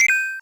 match-ready.wav